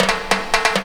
02_12_drumbreak.wav